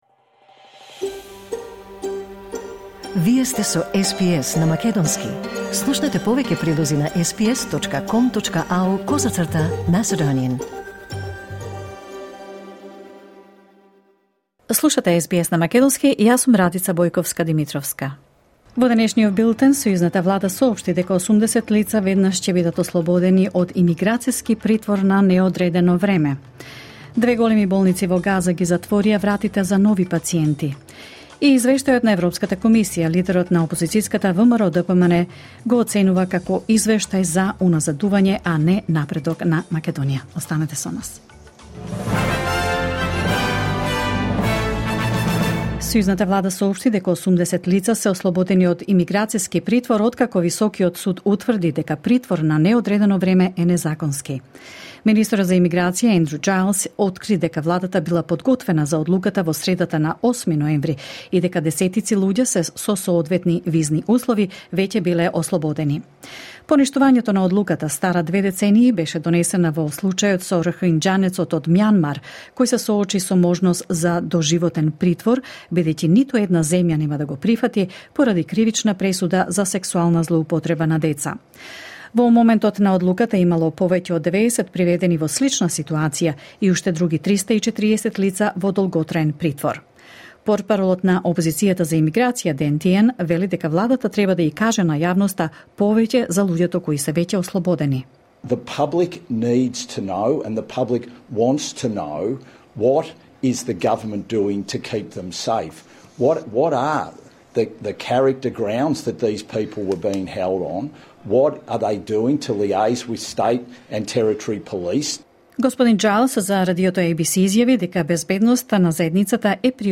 Вести на СБС на македонски 13 ноември 2023
SBS News in Macedonian13 November 2023